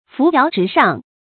注音：ㄈㄨˊ ㄧㄠˊ ㄓㄧˊ ㄕㄤˋ
扶搖直上的讀法